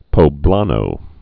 (pō-blänō)